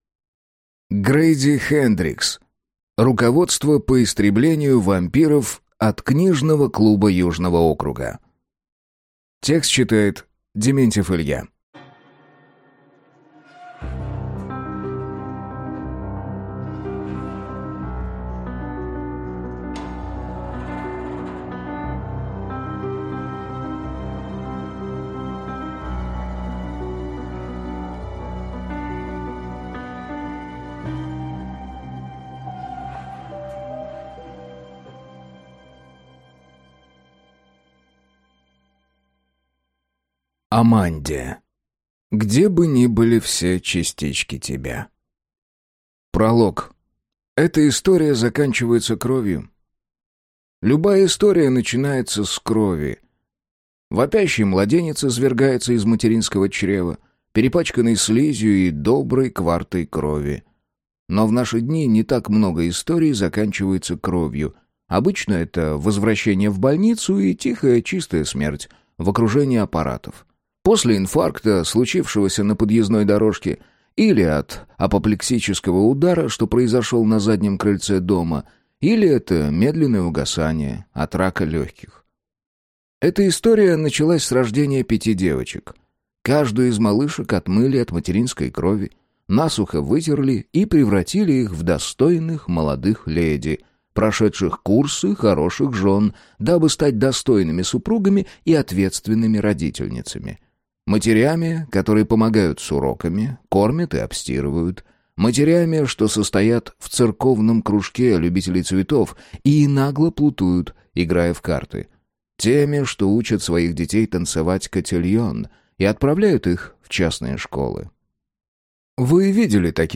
Аудиокнига Руководство по истреблению вампиров от книжного клуба Южного округа | Библиотека аудиокниг